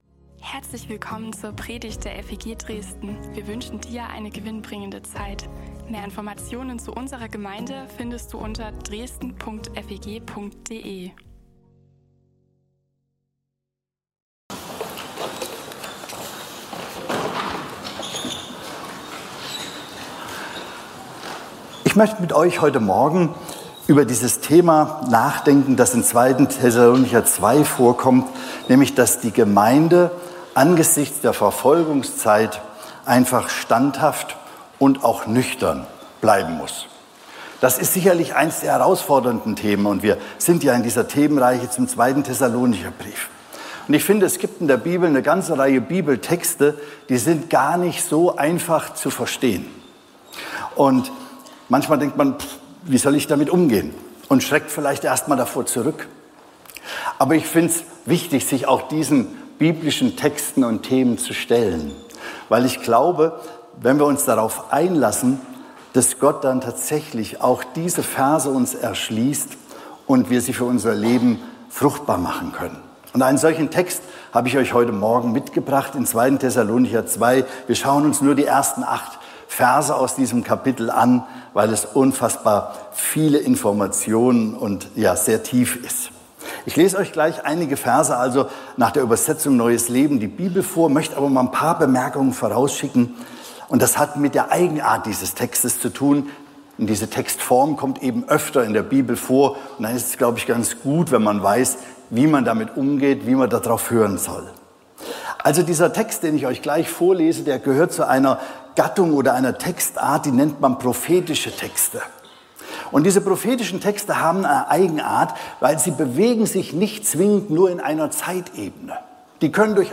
FeG Dresden Predigten und mehr